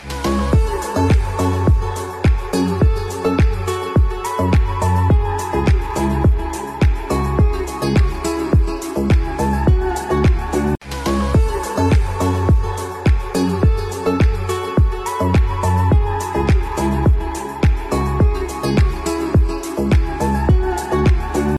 شیک و ملو